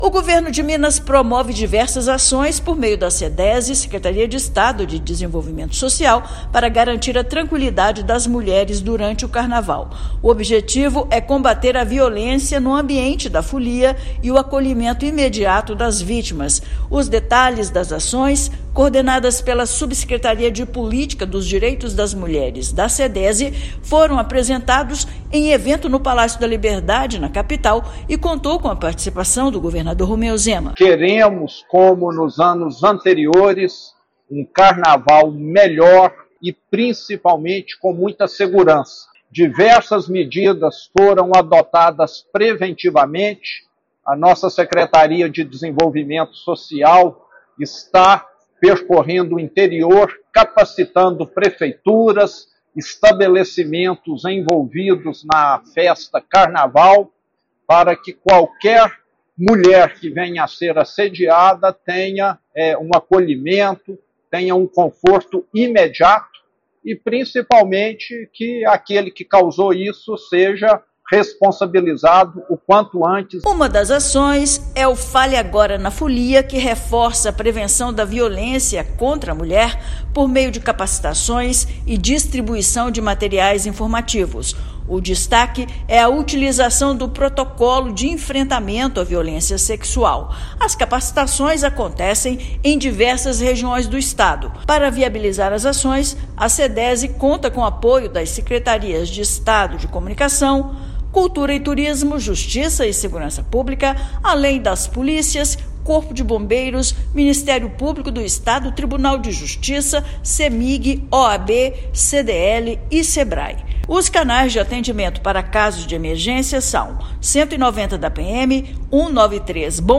Secretaria de Estado de Desenvolvimento Social (Sedese-MG) promove capacitação e atendimento especializado para ampliar a segurança na folia. Ouça matéria de rádio.